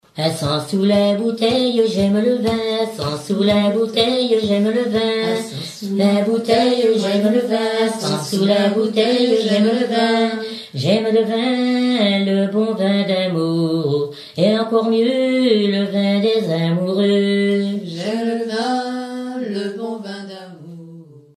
Fonction d'après l'analyste gestuel : à marcher
Genre énumérative
Chanteuse du pays de Redon
Pièce musicale éditée